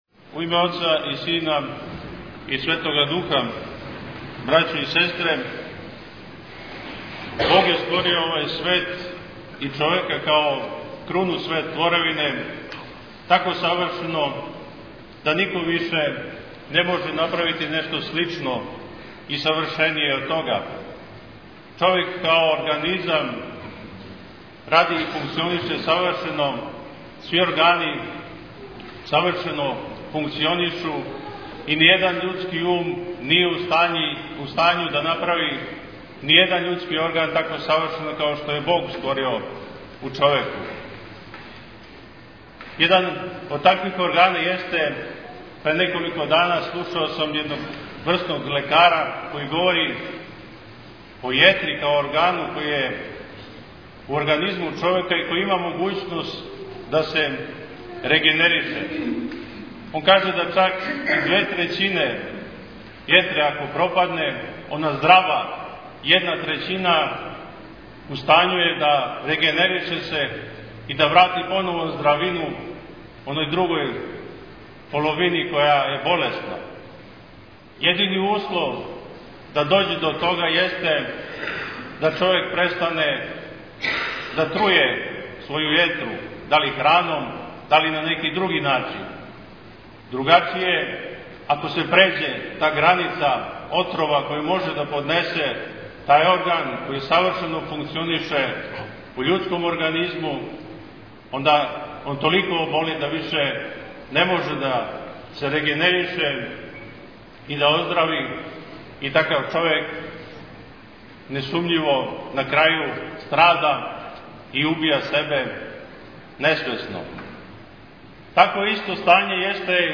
Tagged: Бесједе